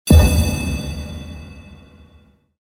level_up.mp3